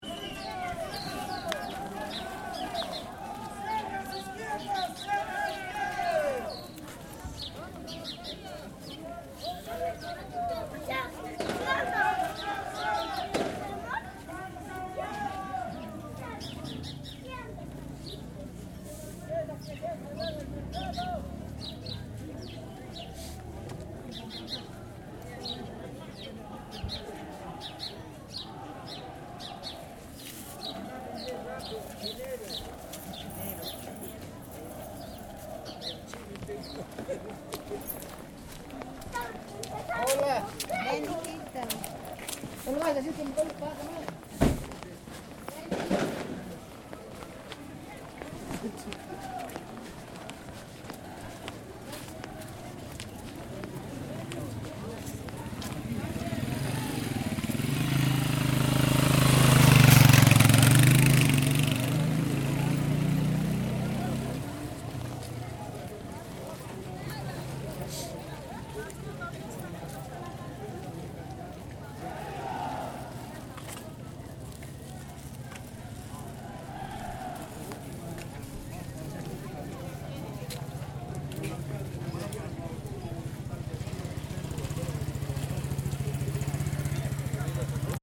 Quelques échauffourées eurent lieu, après plusieurs semaines de blocage, opposant les manifestants aux villageois voisins qui avaient besoin des routes pour accéder à la ville et à leur activité quotidienne. Je me suis promené en ville avec mon micro à ce moment :